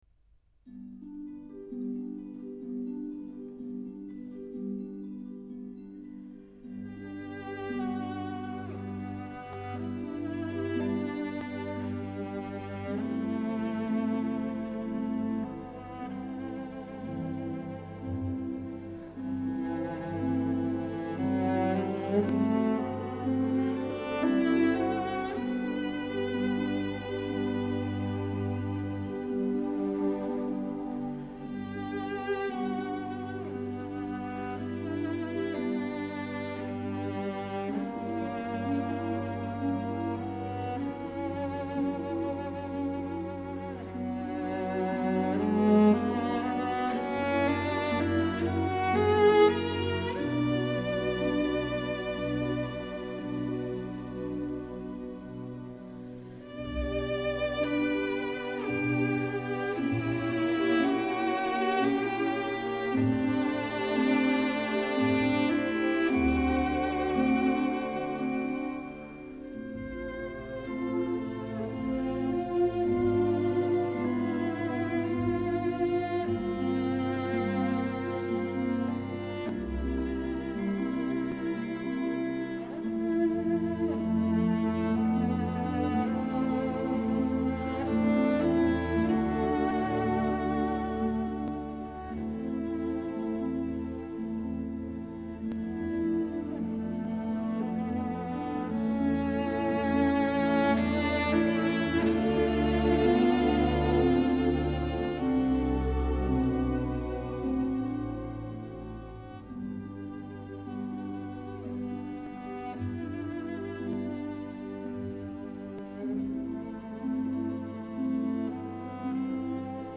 她对乐曲的处理细腻感性，善于表现大提琴歌唱性 强的特色。